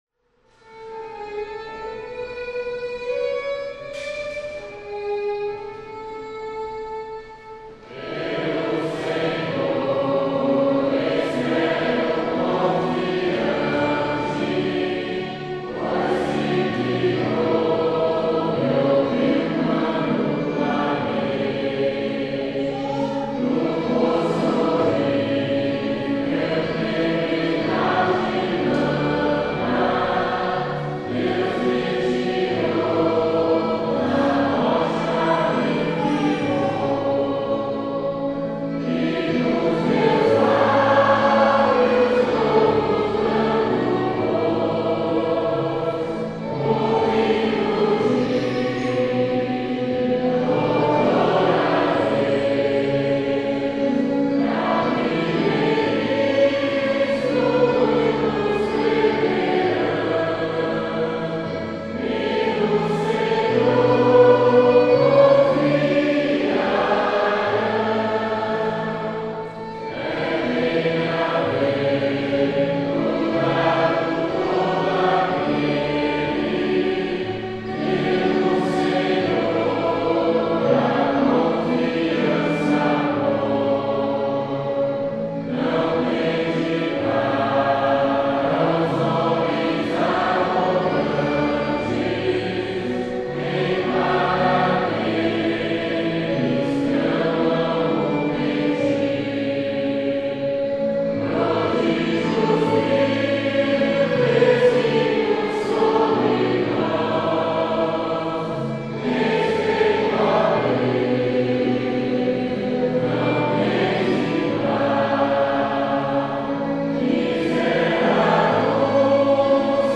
Melodia tradicional sueca
salmo_40B_cantado.mp3